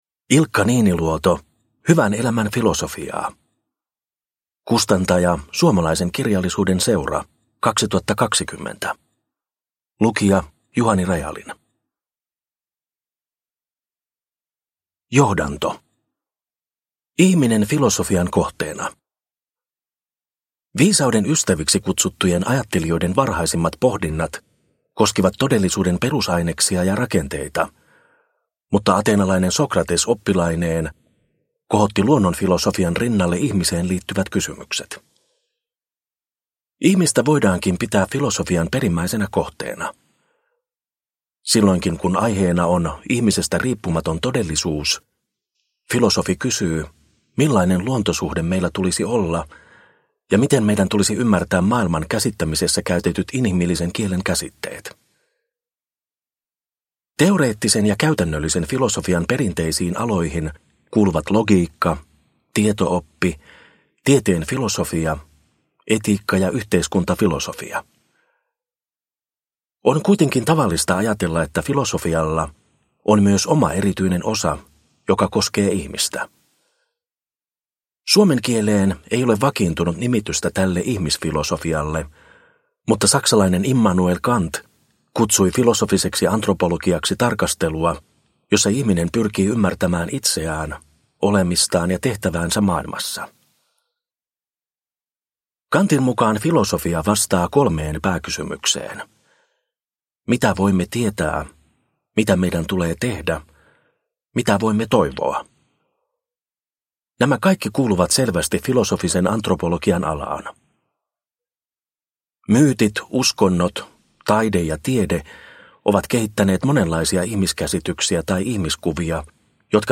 Hyvän elämän filosofiaa – Ljudbok – Laddas ner